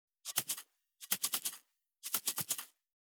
365,調味料固形物,カシャカシャ,サラサラ,パラパラ,ジャラジャラ,サッサッ,ザッザッ,シャッシャッ,シュッ,パッ,
効果音厨房/台所/レストラン/kitchen
ペットボトル